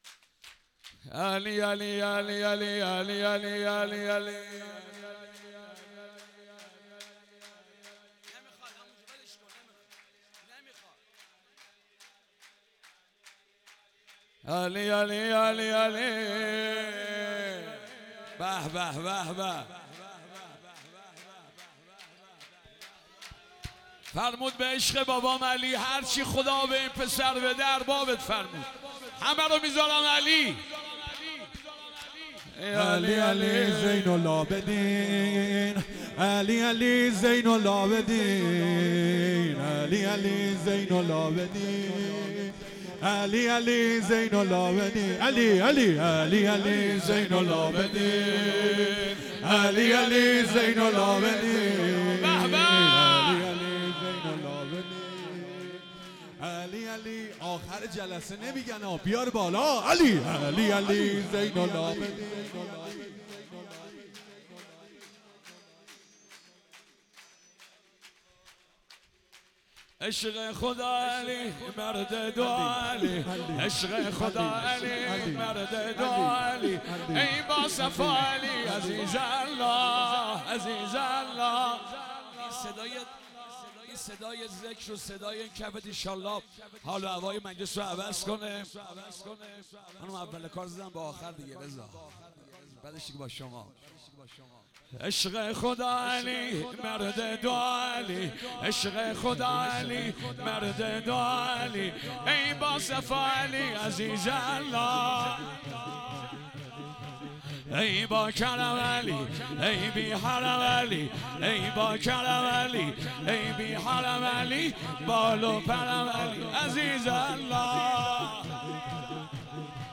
جشن ولادت امام سجاد(ع)
سرود